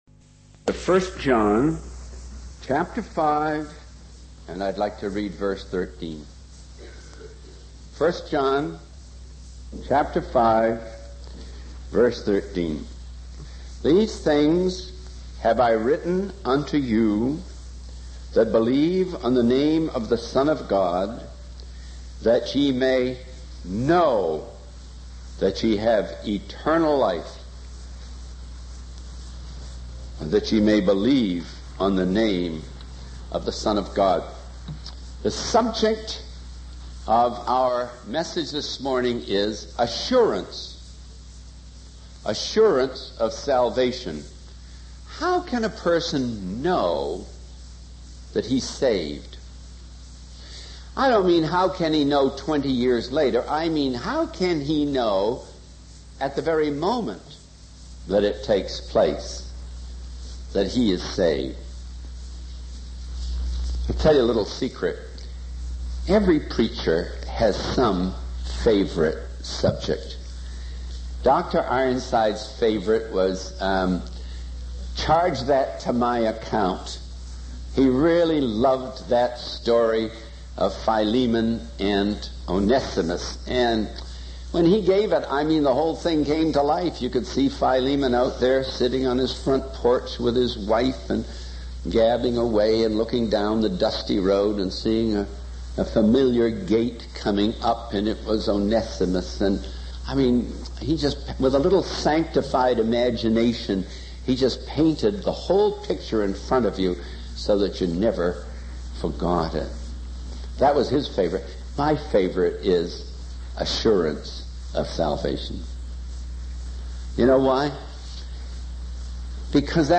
In this sermon, the speaker discusses the topic of salvation and the different ways people believe they can earn it. He emphasizes that salvation cannot be earned through good works or by living a good life. Instead, salvation is a gift from God that is received through faith.